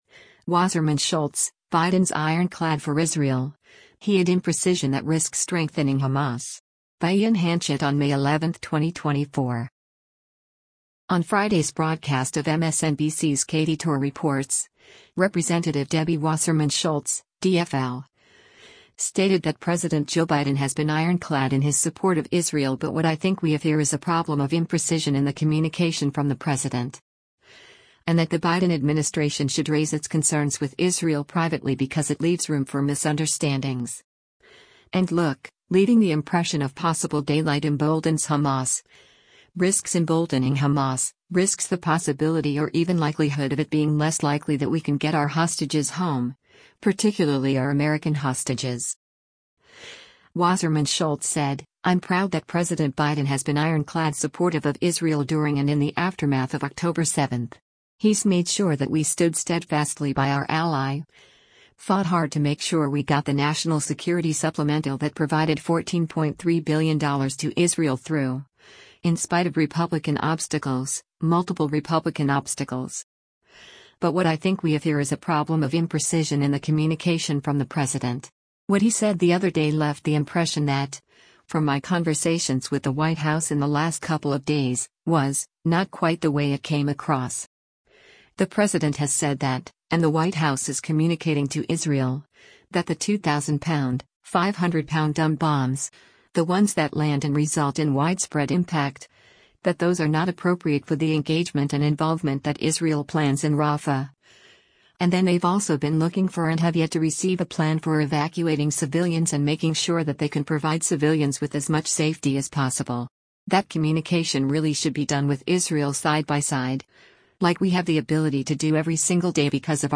On Friday’s broadcast of MSNBC’s “Katy Tur Reports,” Rep. Debbie Wasserman Schultz (D-FL) stated that President Joe Biden “has been ironclad” in his support of Israel but “what I think we have here is a problem of imprecision in the communication from the President.”
Guest host José Díaz-Balart then asked, “Do you think, Congresswoman, that the debate and discussion that we see on Capitol Hill, on college campuses throughout our country about the American involvement in Israel and with Israel should change?